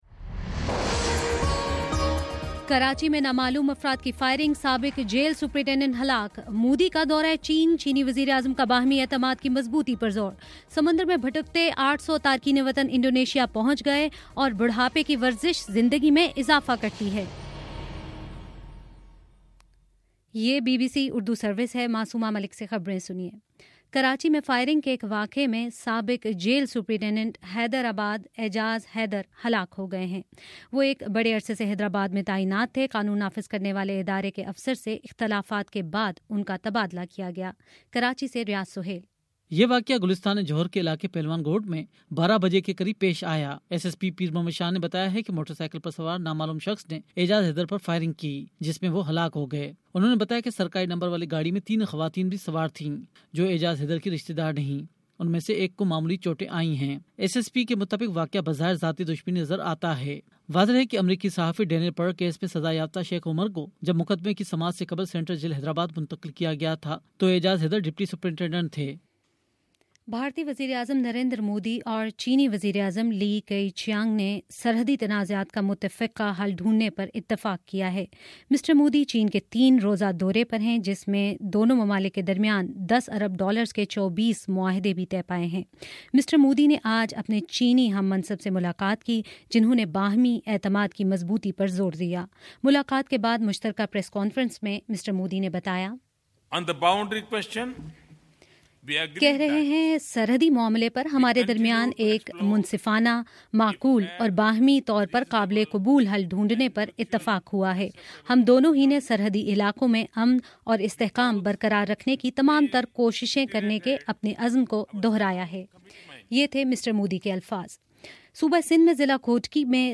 مئی 15: شام چھ بجے کا نیوز بُلیٹن